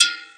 pbs - nyc [ Perc ].wav